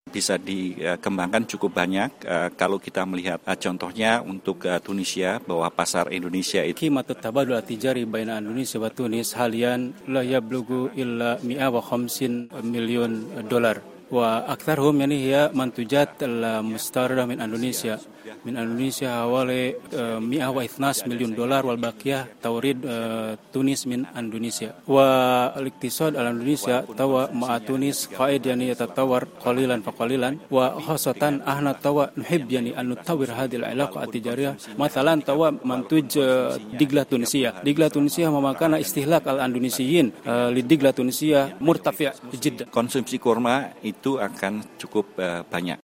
قال سفير اندونيسيا لدى تونس، روني براستيو يوليانتورو، في تصريح لـ "الجوهرة أف أم" اليوم الثلاثاء إن بلده يسعى لتطوير العلاقات التجارية مع تونس.
وأفاد على هامش يوم إعلامي حول اندونيسيا انتظم اليوم في سوسة، أن قيمة التبادل الجاري حاليا بين تونس واندونيسيا تقدر حاليا بنحو 150 مليون دولار، تستأثر اندونيسيا بنصيب الأسد منها وبقيمة 112 مليون دولار.